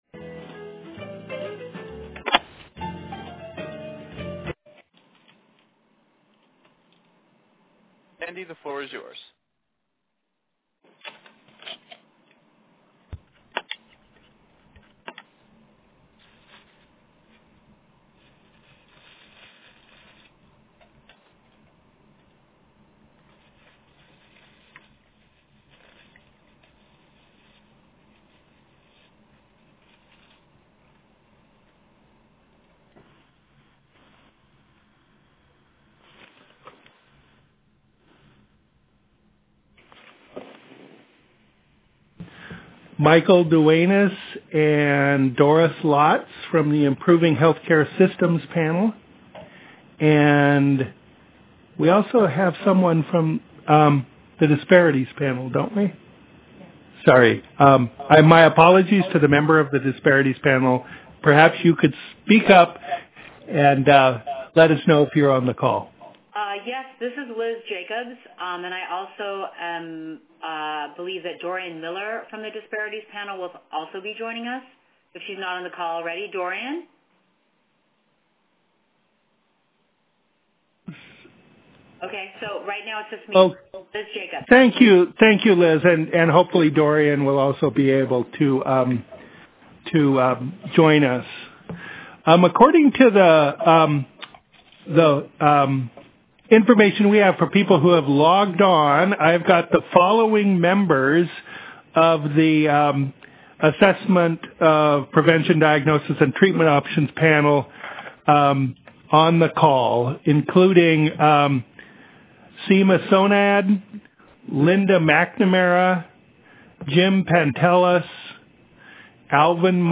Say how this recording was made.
Listen to the Teleconference Audio Recording